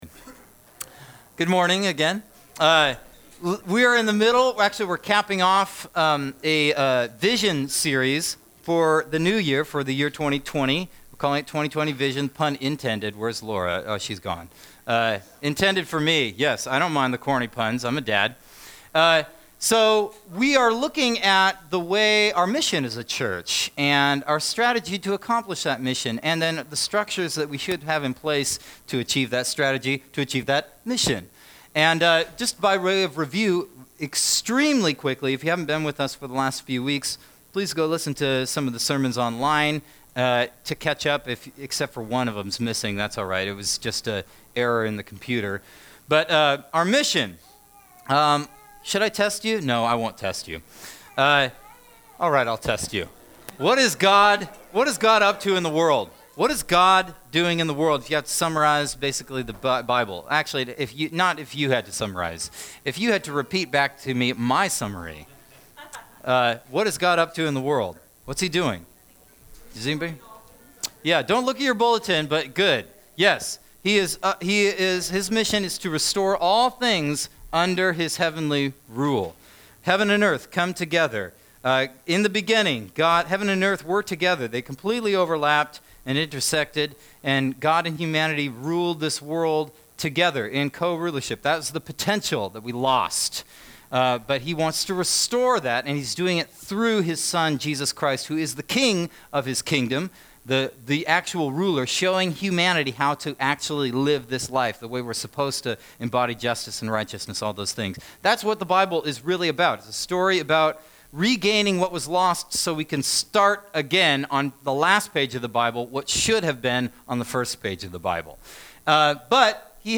Sermons - Wapato Valley Church